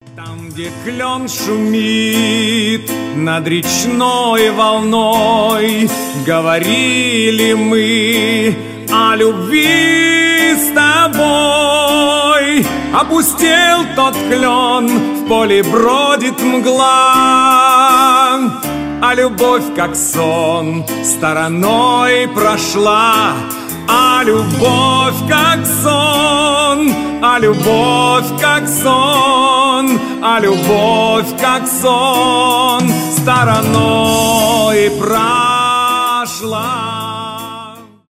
эстрадные